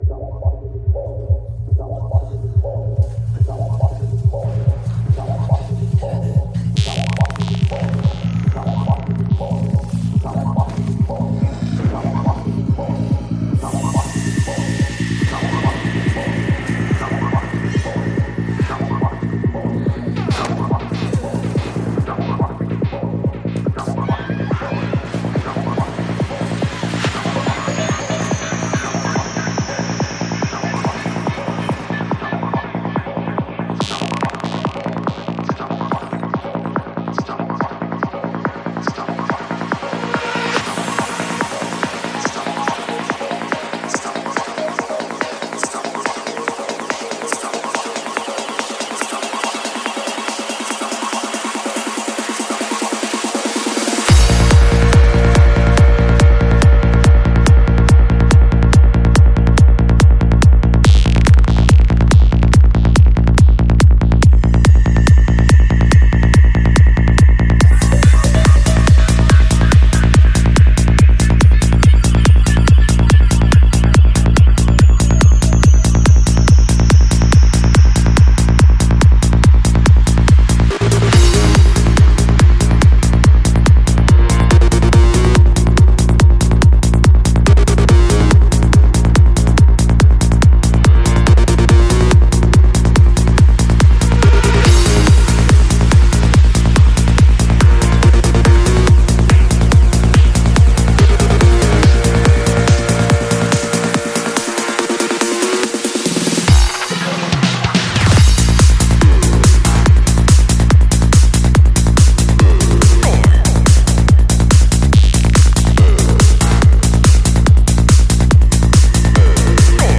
Жанр: Транс
Альбом: Psy-Trance